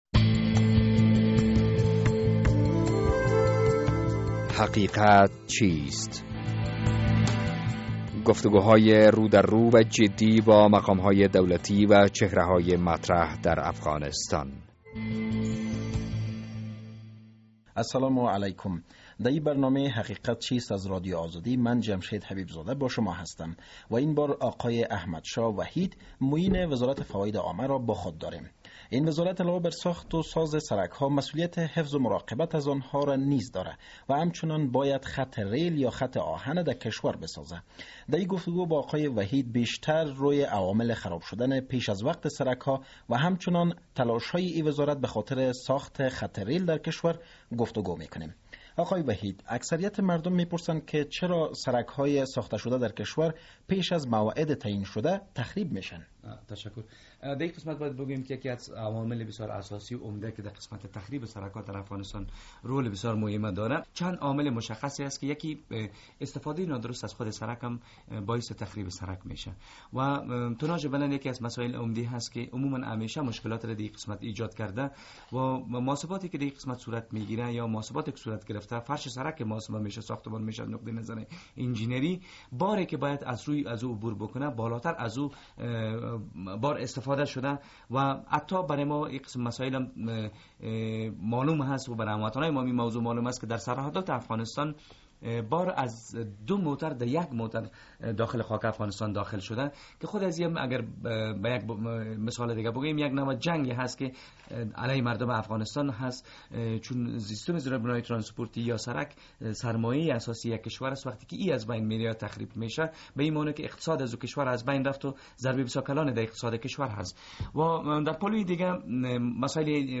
در این برنامهء حقیقت چیست، با داکتر احمد شاه وحید معین وزارت فواید عامه افغانستان گفتگو کرده ایم.